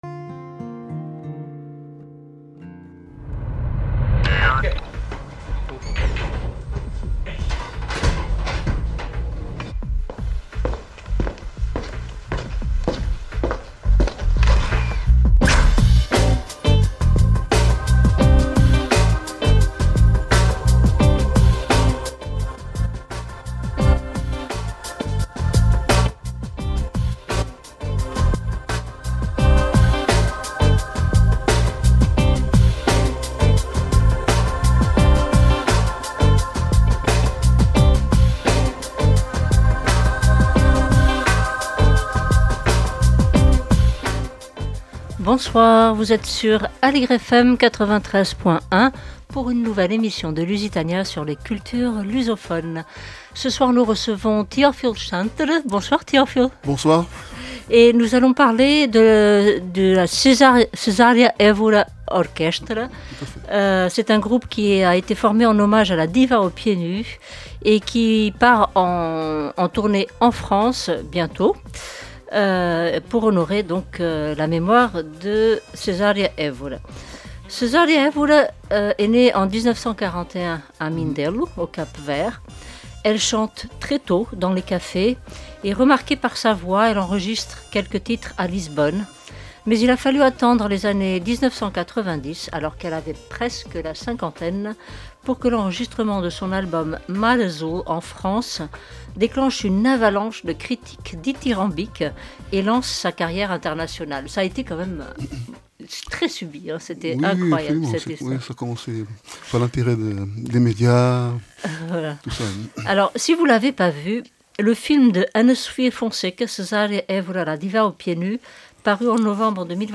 Ce samedi, Lusitania a reçu Teofilo Chantre, compositeur interprète, un des membres du Cesaria Evora Orchestra, ensemble qui parcourt le monde pour rendre hommage à la "diva aux pieds nus".